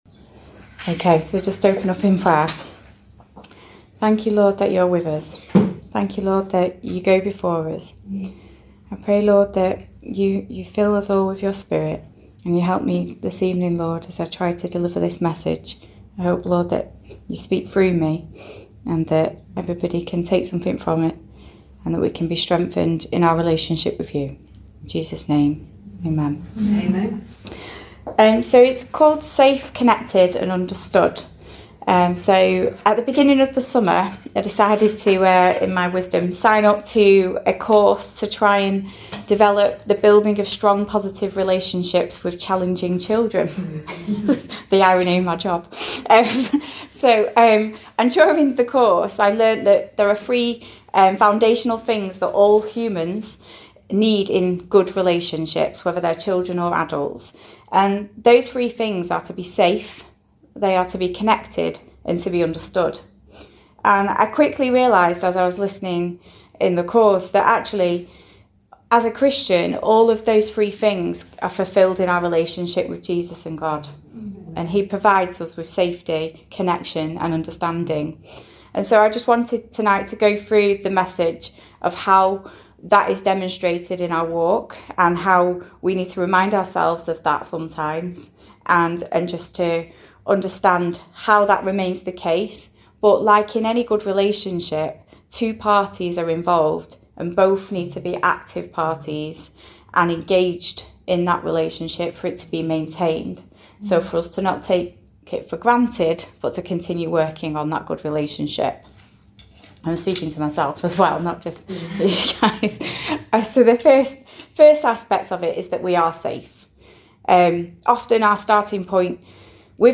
Ladies message “Safe